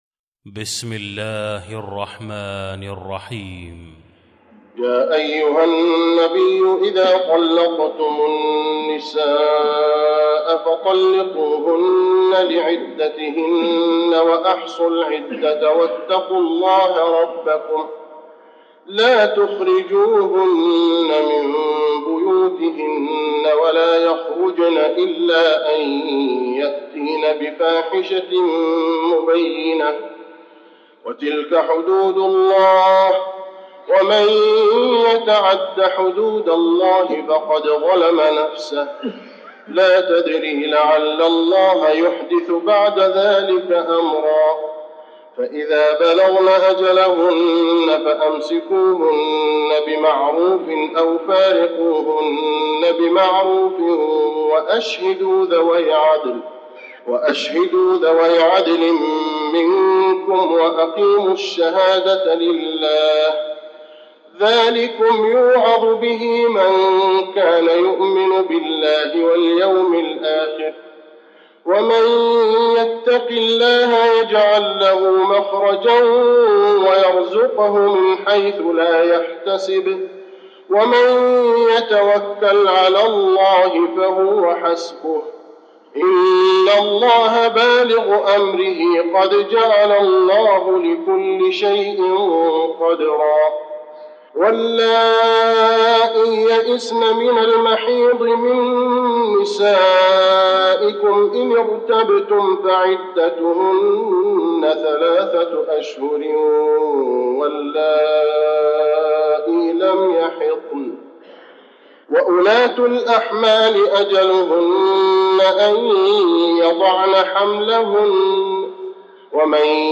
المكان: المسجد النبوي الطلاق